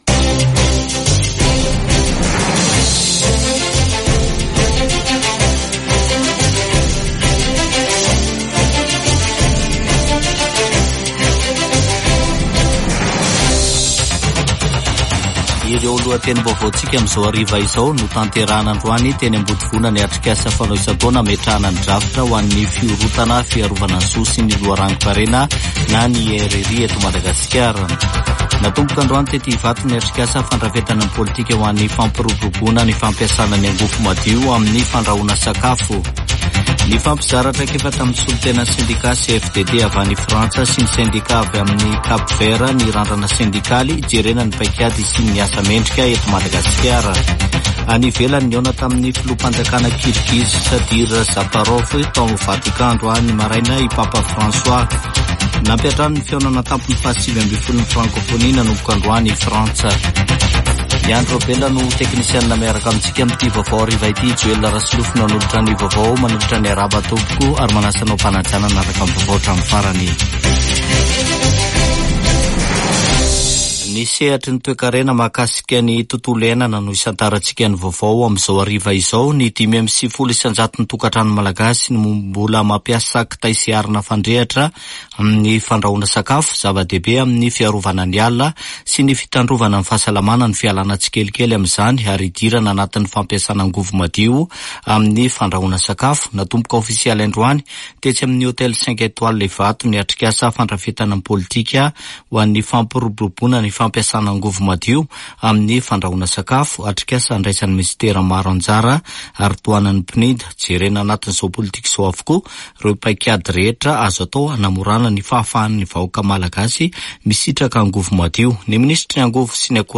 [Vaovao hariva] Zoma 4 ôktôbra 2024